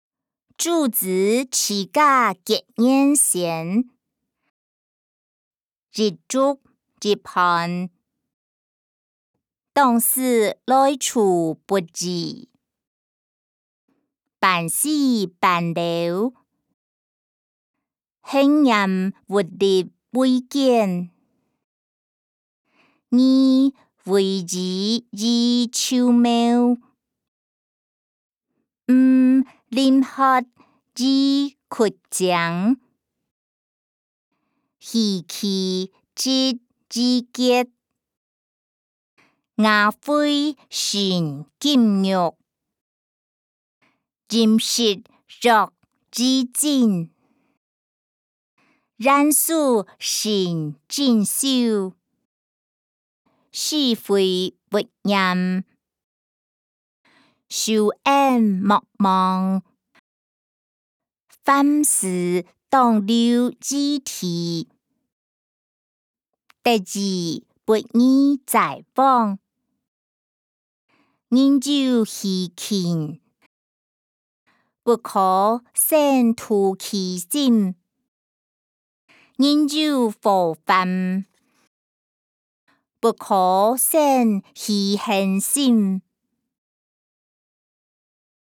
歷代散文-朱子治家格言選音檔(海陸腔)